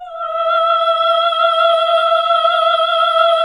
AAH E3 -L.wav